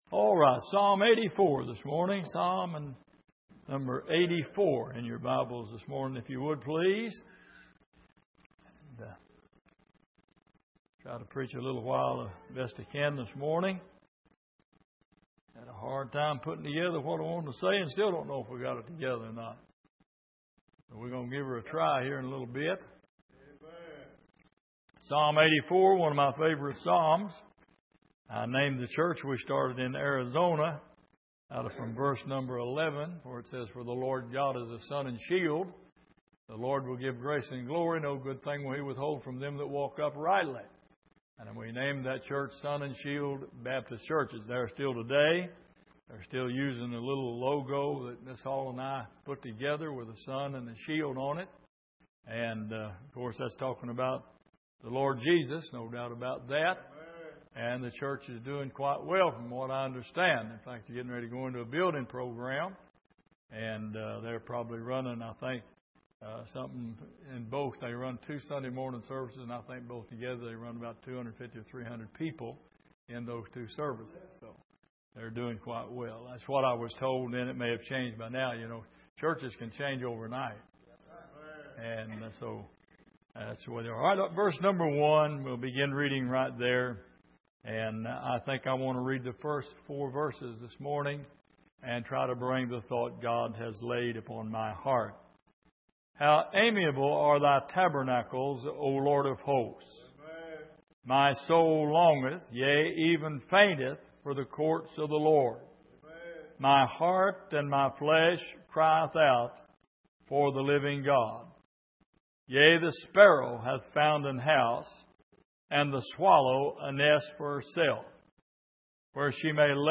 Passage: Psalm 84:1-4 Service: Sunday Morning